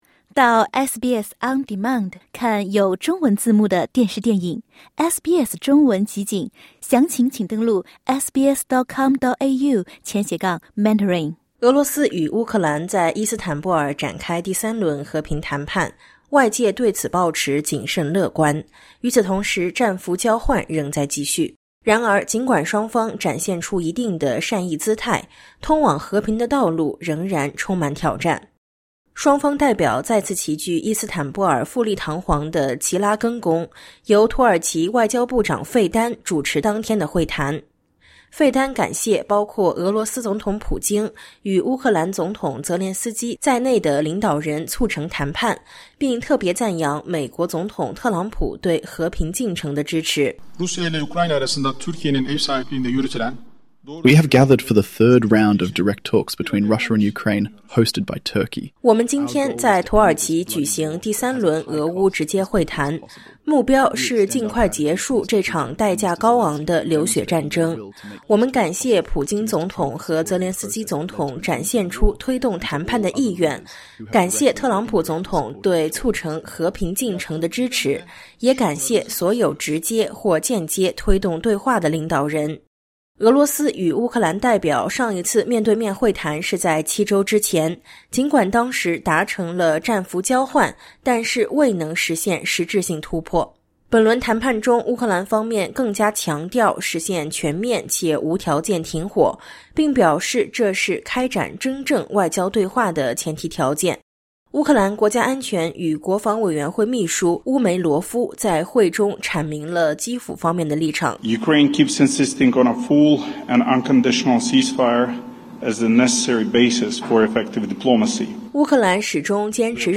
俄罗斯与乌克兰在伊斯坦布尔重启和平谈判，乌克兰方面要求将全面停火作为进一步外交谈判的前提。尽管双方释放出有限的合作信号，但有关领导人峰会的可能性，以及平民命运等关键议题，依然悬而未决。点击音频，收听完整报道。